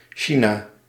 Ääntäminen
US : IPA : [ˈtʃaɪ.nə]